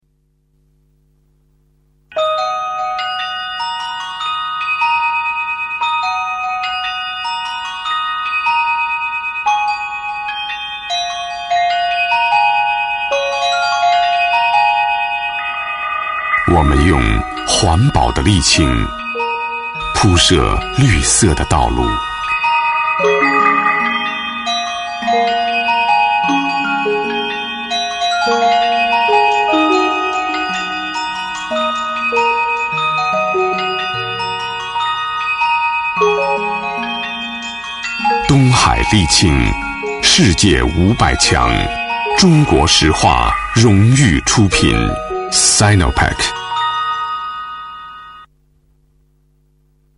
宣传片配音
标签： 磁性
配音风格： 力度 厚重 沉稳 磁性 舒缓